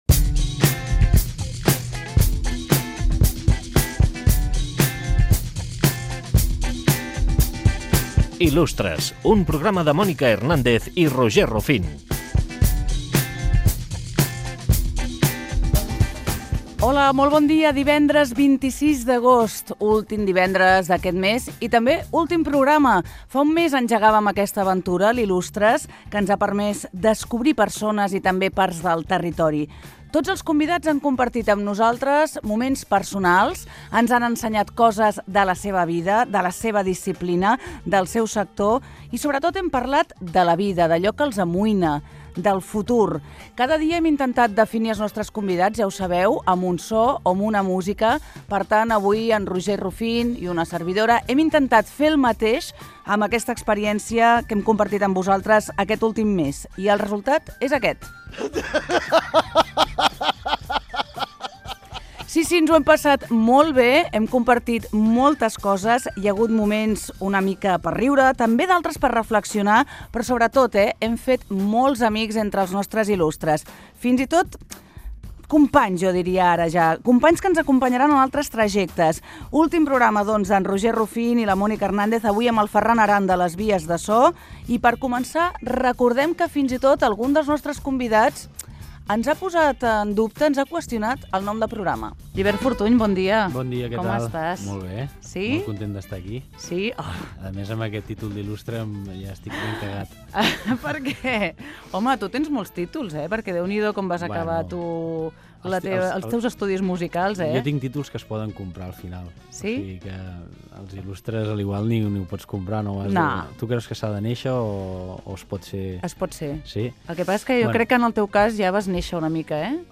Fragment de la última edició del programa.
Cultura